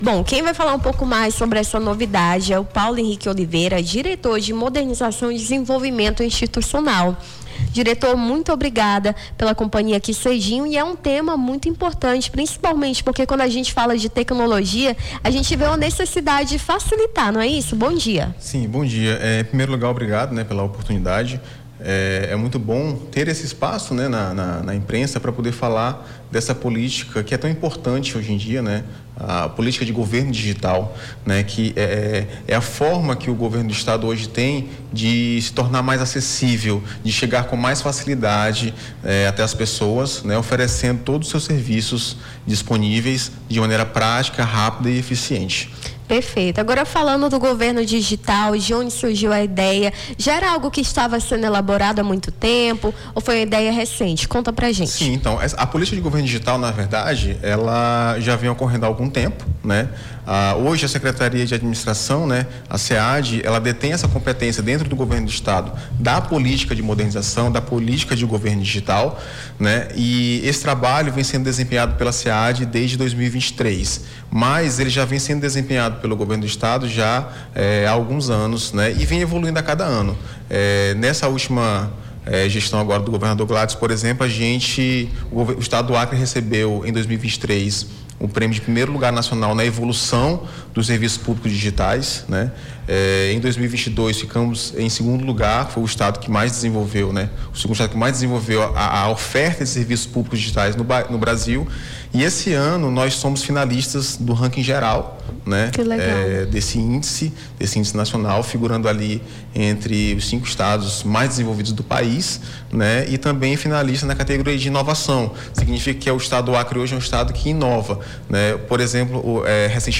Nome do Artista - CENSURA - ENTREVISTA (GOVERNO DIGITAL NO ACRE) 02-07-25.mp3